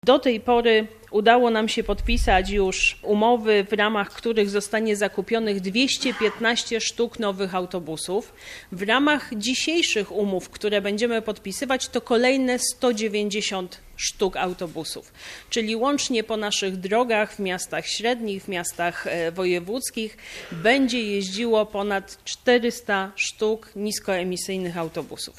Podczas uroczystości minister funduszy i polityki regionalnej Małgorzata Jarosińska-Jedynak mówiła, że transport zbiorowy w Polsce jest coraz bardziej ekologiczny.